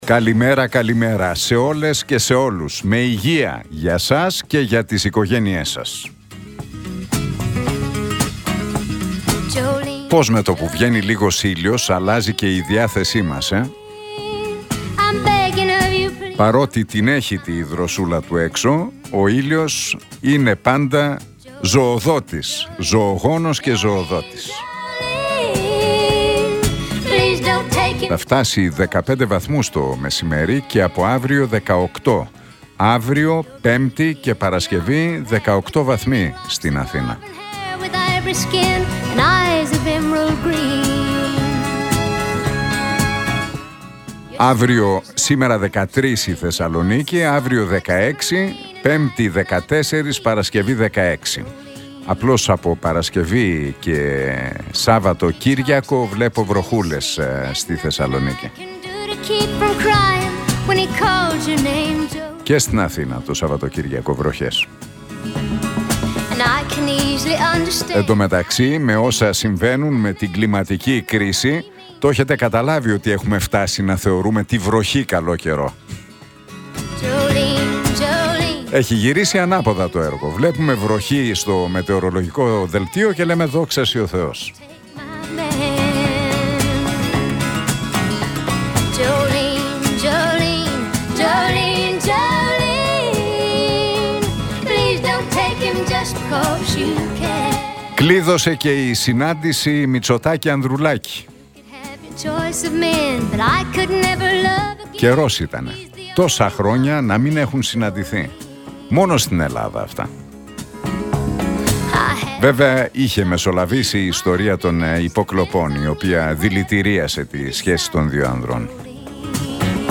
Ακούστε το σχόλιο του Νίκου Χατζηνικολάου στον ραδιοφωνικό σταθμό RealFm 97,8, την Τρίτη 26 Νοεμβρίου 2024.